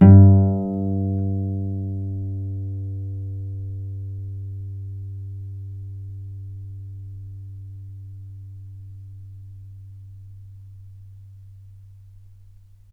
vc_pz-G2-mf.AIF